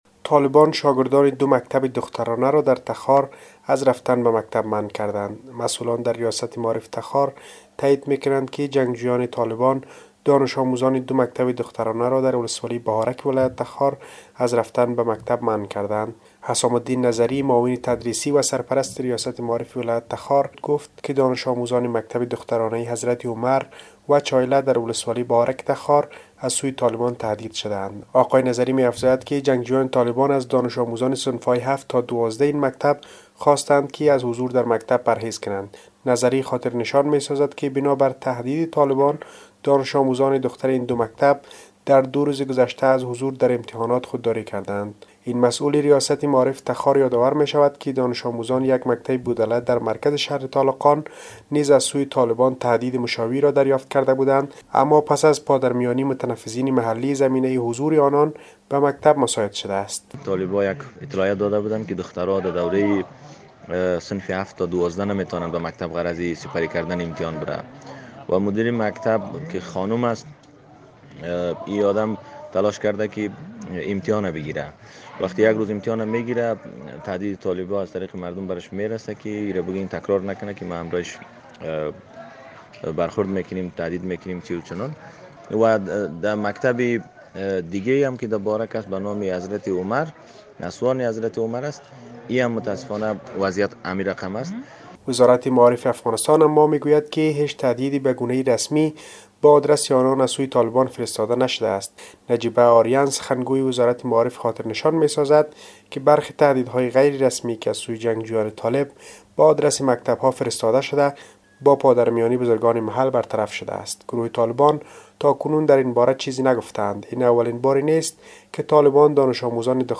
به گزارش خبرنگار رادیودری، مسئولان در ریاست معارف ولایت تخار تأیید می‌کنند که طالبان دانش‌آموزان دو مکتب دخترانه را در ولسوالی بهارک ولایت تخار از رفتن به مکتب منع کرده اند.